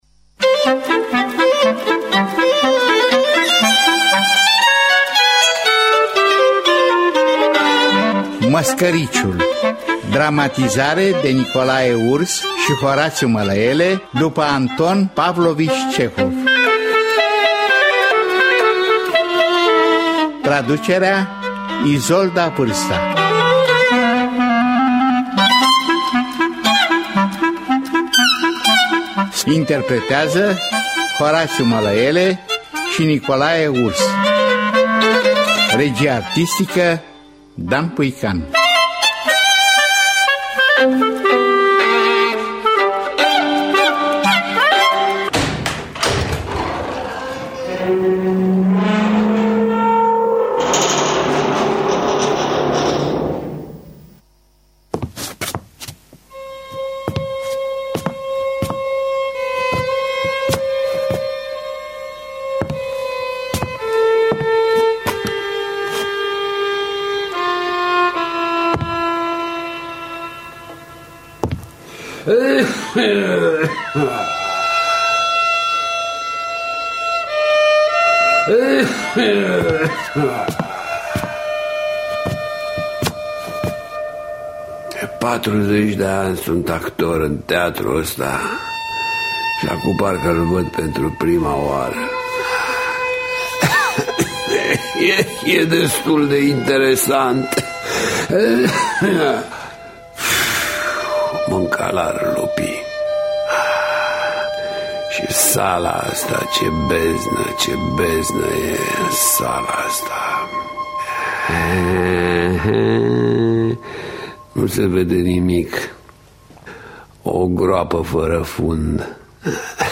Dramatizarea radiofonică de Nicolae Urs şi Horaţiu Mălăele.
În distribuţie Horaţiu Mălăele şi Nicolae Urs.